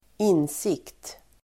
Uttal: [²'in:sik:t]